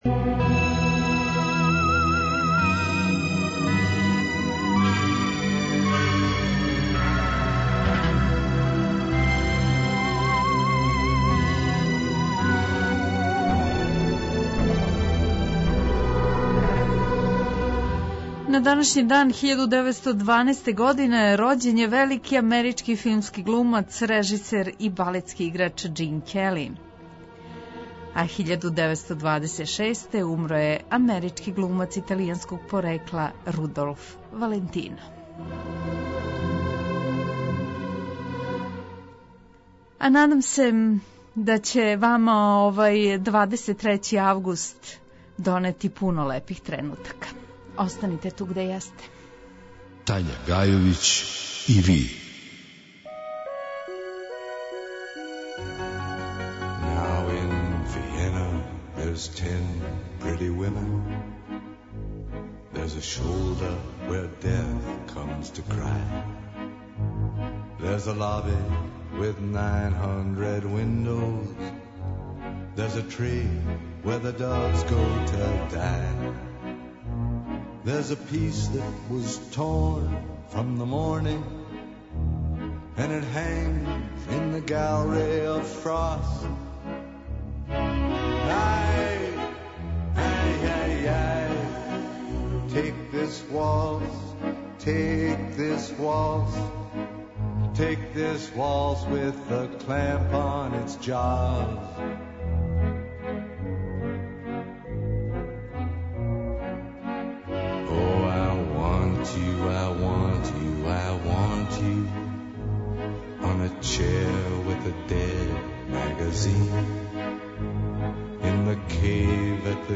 Специјално за Ноћни програм говорили су